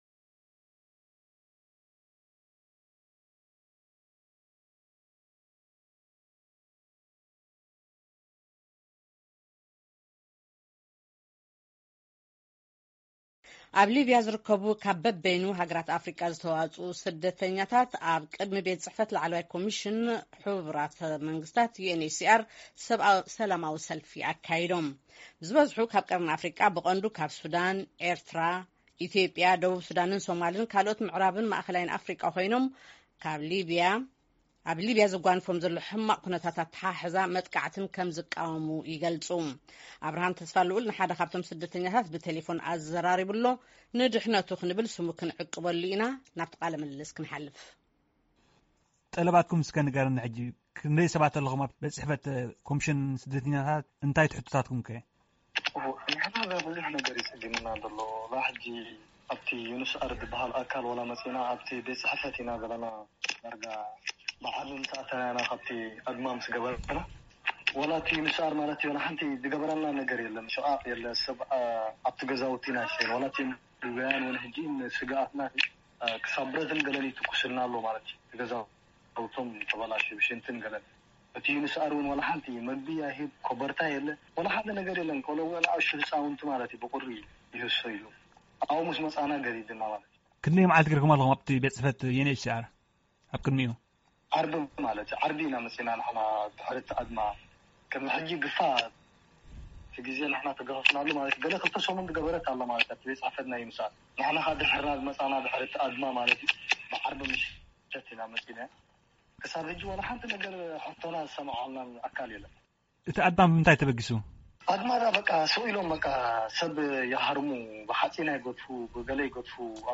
ንህልው ኩነታት ስደተኛታት ኣብ ሊብያ ዝምልከት ቃለ መጠይቕ ምስ ኤርትራዊ ስደተኛ
ዝበዝሑ ካብ ቀርኒ ኣፍሪቃ፡ ብቐንዱ ከኣ ሱዳን፡ ኤርትራ፡ ኢትዮጵያ፡ ደቡብ ሱዳንን ሶማልን ካልኦት ምዕራብን ማእከላይን ኣፍሪቃ ኰይኖም፡ ኣብ ሊብያ ንዘጓንፎም ዘሎ ሕማቕ ኣተሓሕዛን መጥቃዕቲን ከም ዝቃወሙ ይሕብሩ። ንሓደ ካብቶም ስደተኛታት ብቴለፎን ኣዘራሪብናዮ ኣለና፡ ንድሕነቱ ስሙ ዓቒብናዮ አለና ።